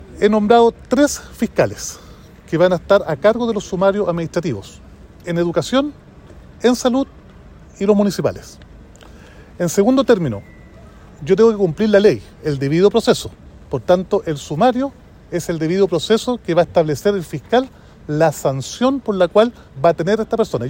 “He nombrado tres fiscales, que van a estar a cargo de los sumarios administrativos en Educación, en Salud y los Municipales. En segundo término, yo tengo que cumplir la ley, el debido proceso, por tanto, el sumario es el debido proceso (…)”, comentó el jefe comunal.